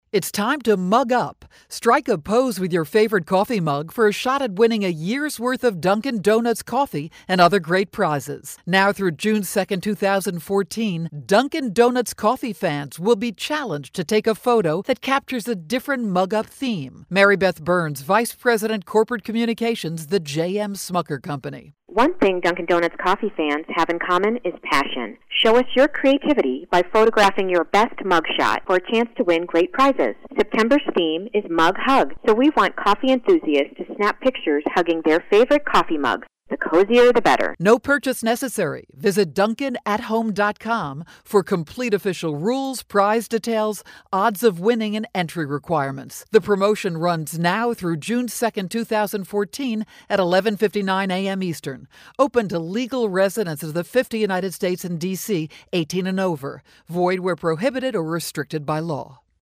September 18, 2013Posted in: Audio News Release